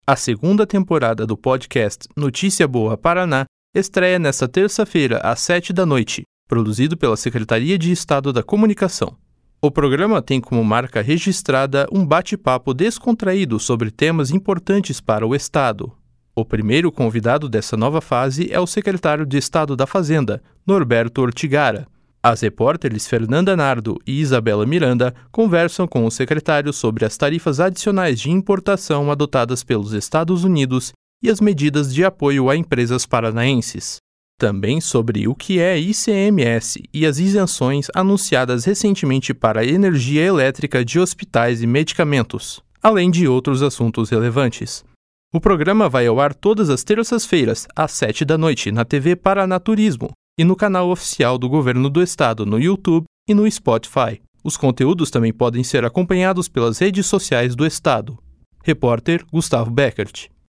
A segunda temporada do podcast Notícia Boa Paraná estreia nesta terça-feira, às 7 da noite, produzido pela Secretaria de Estado da Comunicação. O programa tem como marca registrada um bate-papo descontraído sobre temas importantes para o Estado.